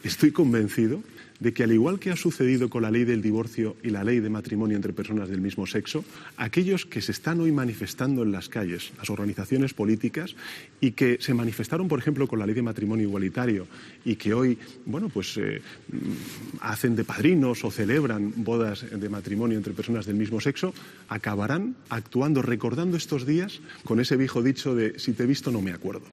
En una entrevista en TVE, el presidente del Gobierno también ha defendido de nuevo la figura del verificador internacional pactada por PSOE y Junts, insistiendo en que se trata de un "mecanismo excepcional" para facilitar el diálogo y eventualmente el acuerdo, al tiempo que no ha querido confirmar que la primera reunión con los de Carles Puigdemont se vaya a producir en Ginebra este sábado.